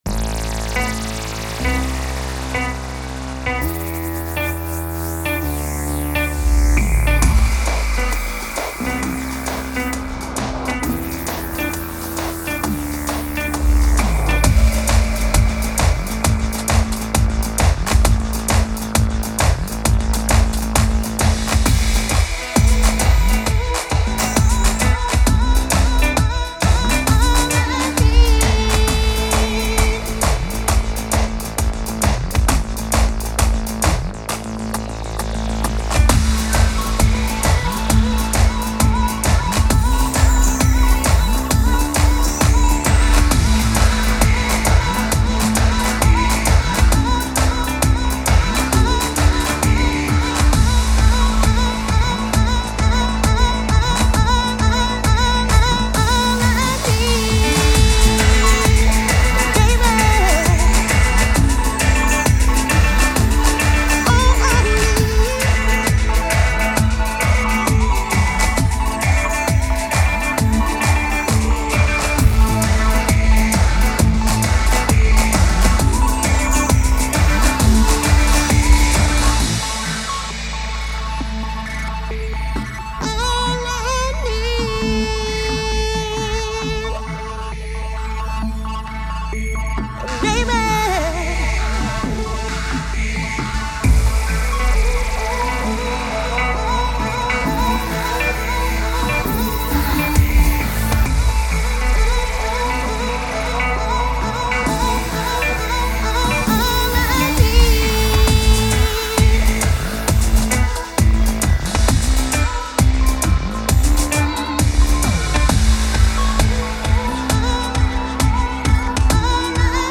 House Contest 1 - Voting Thread - Siegertreppchen im Startpost!
(hauptaugenmerk bei diesem track war für mich bassbereich und stereospektrum und ich bin mit -13 LUFS weit weg von dem, was ozone vorschlägt...)